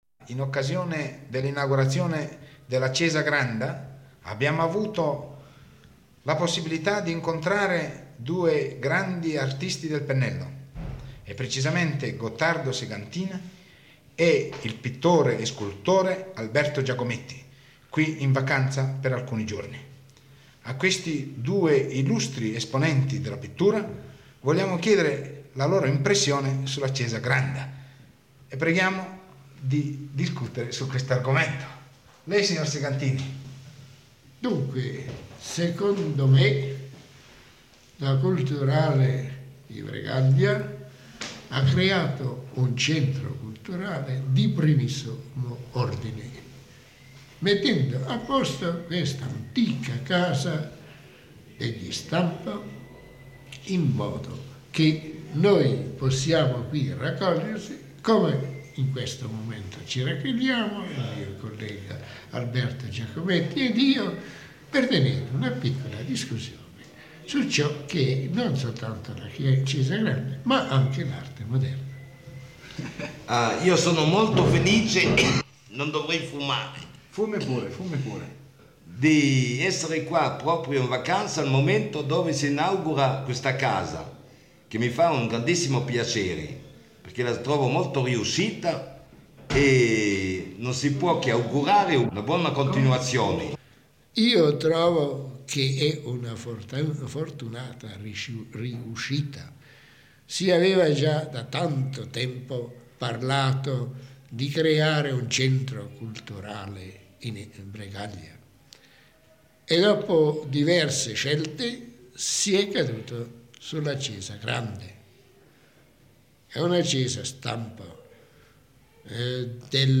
In occasione dell’inaugurazione del Museo Ciäsa Granda a Stampa
intervista